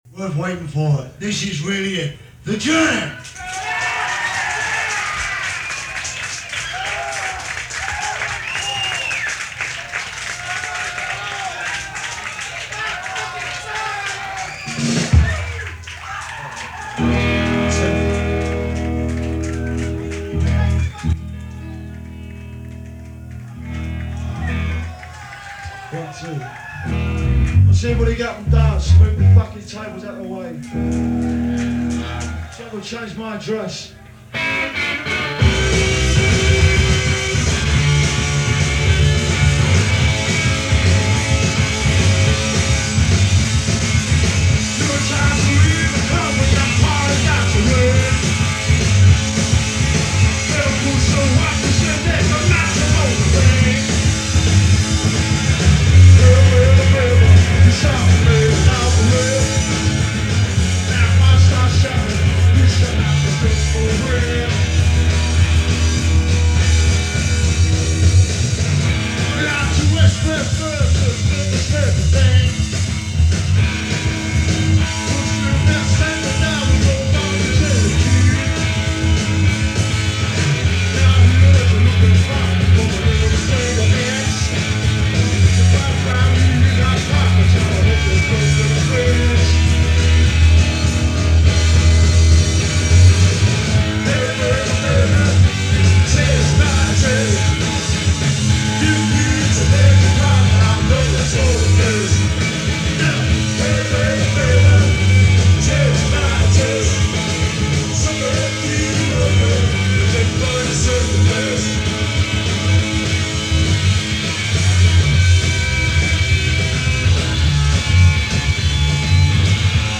The Punk/New Wave/Mod Revival Years.